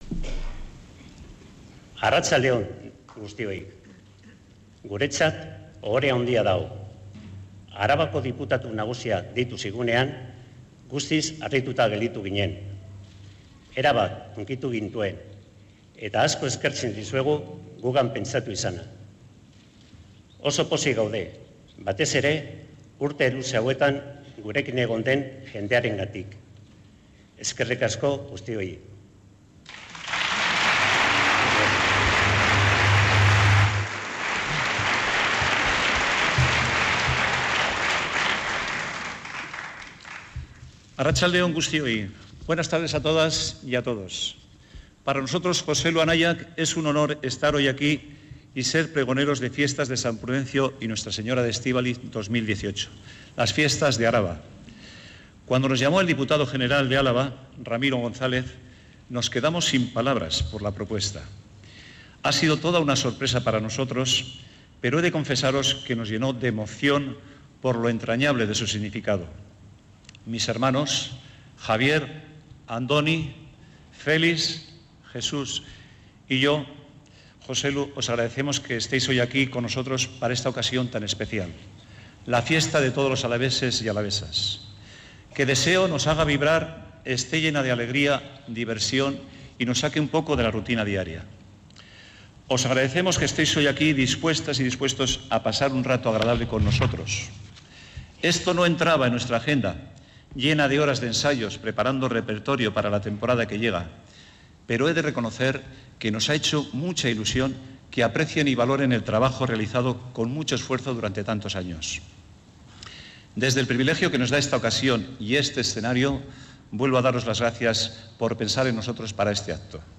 Pregón de San Prudencio 2018